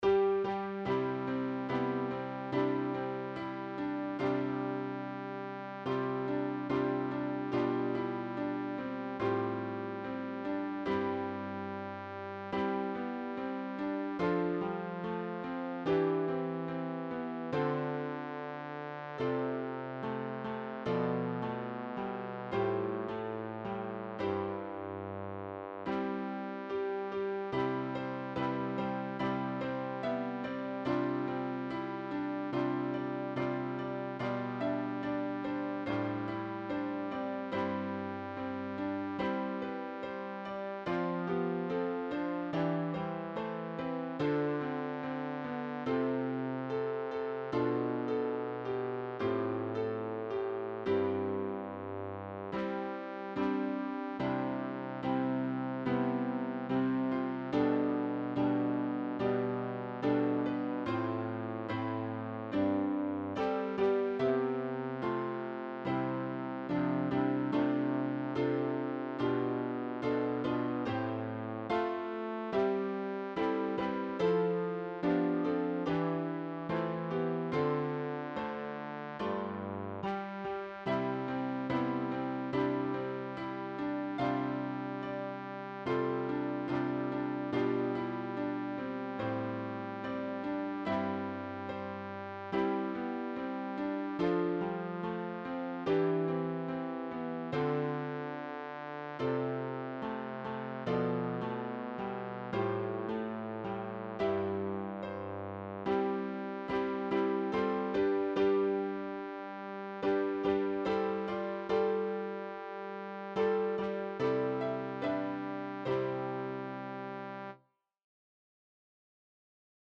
Übehilfen für das Erlernen von Liedern